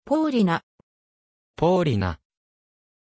Pronunciation of Paulina
pronunciation-paulina-ja.mp3